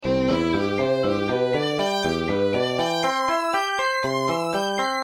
Sonificação do número de faces, vértices e arestas dos Sólidos Platónicos
instrument: marimba - nº faces guitarra elétrica - nº vértices violino - nº vértices key: C Maior scale range: 2 octaves